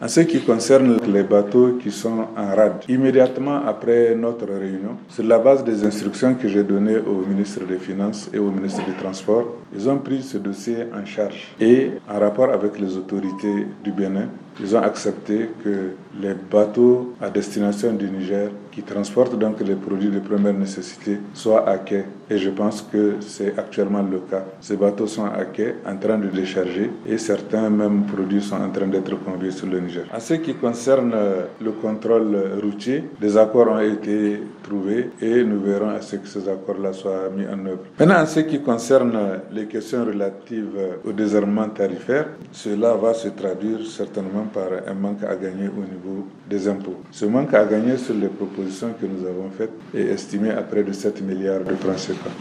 Interview de Ouhoumoudou Mahamadou